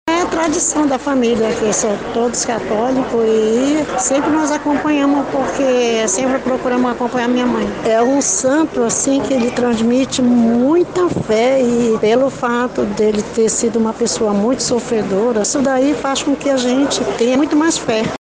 SONORA-3-PROCISSAO-SAO-SEBASTIAO-2.mp3